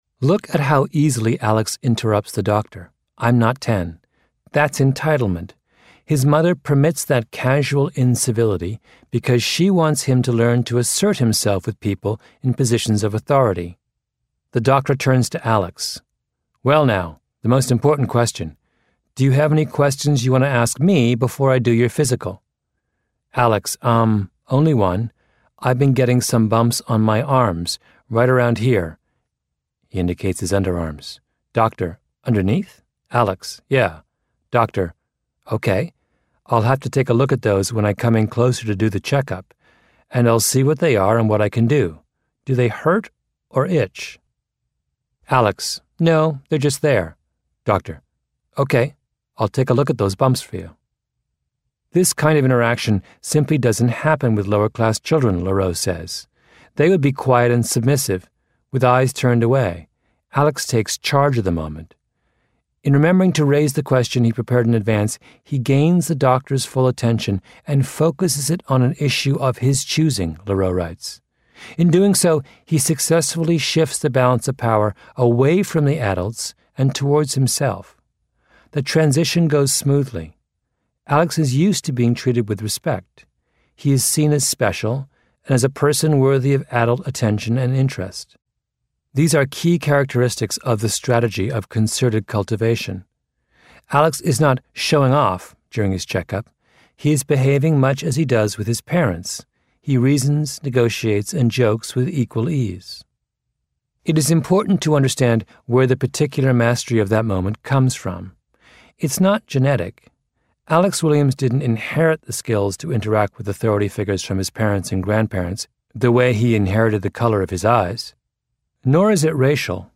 在线英语听力室异类之不一样的成功启示录 第70期:孩子的交流技巧需要培养的听力文件下载, 《异类:不一样的成功启示录Outliers:The Story of Success》是外文名著，是双语有声读物下面的子栏目，栏目包含中英字幕以及地道的英语音频朗读文件MP3，通过学习本栏目，英语爱好者可以懂得不一样的成功启示，并在潜移默化中挖掘自身的潜力。